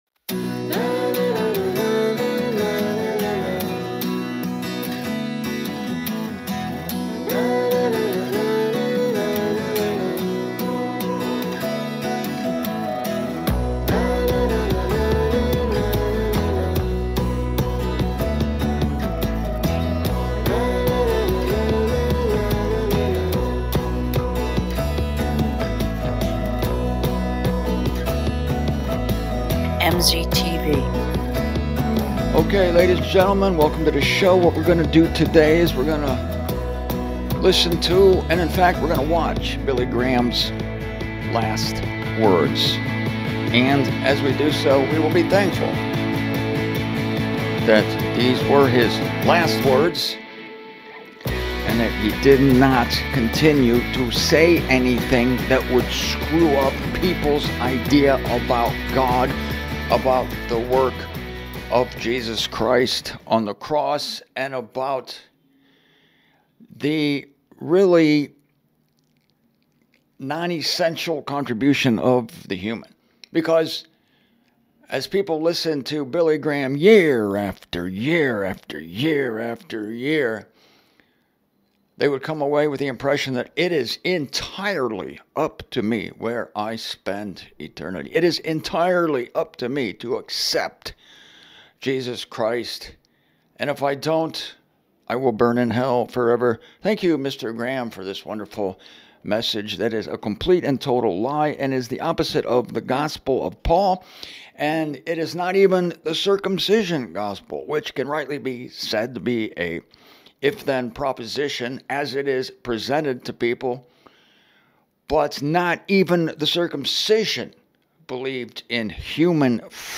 You can tell this at the end when I practically cry for the fact that God made him a vessel of dishonor.